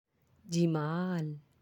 (jamal)
How to say Beauty